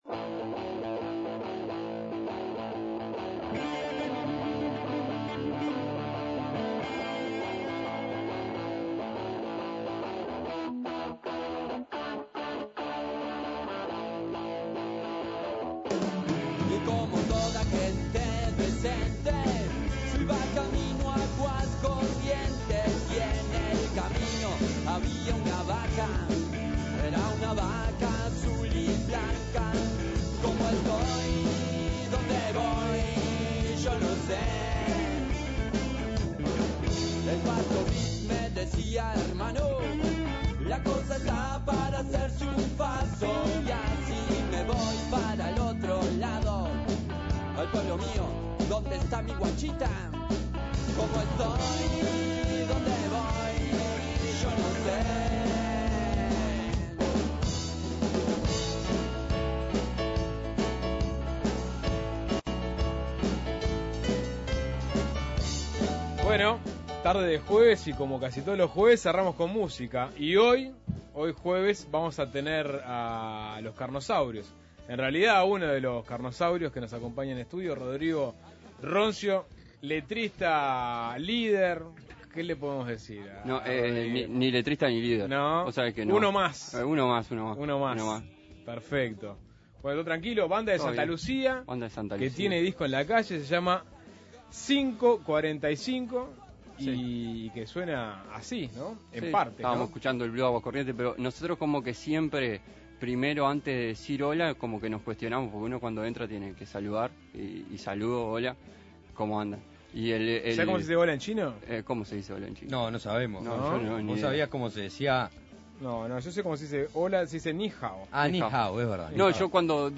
Uno de los Carnosaurios visitó la radio para cerrar Suena Tremendo.
El grupo, que en 2006 obtuvo el primer premio del Festival Rockarlitos en Las Piedras, se mueve dentro del Ska, el Reggae y el Funk.